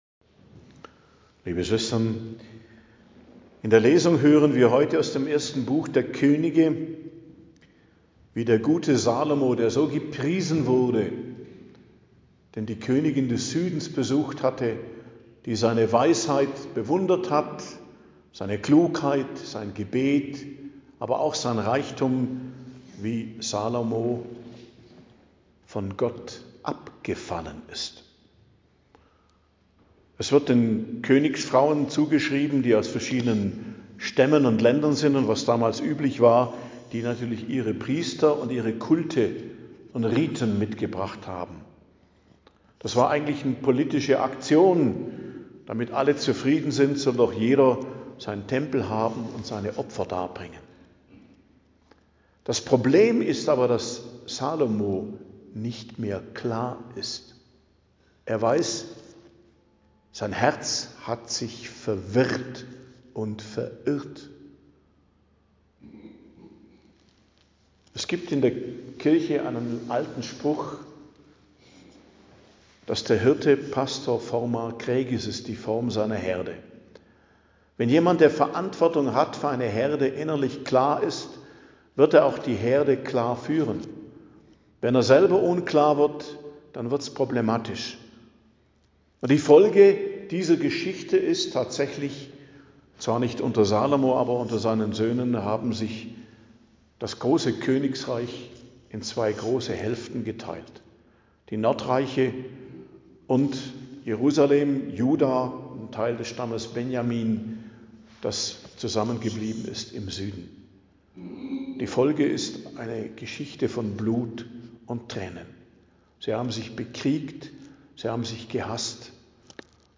Predigt am Donnerstag der 5. Woche i.J., 12.02.2026